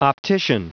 Prononciation du mot optician en anglais (fichier audio)
Prononciation du mot : optician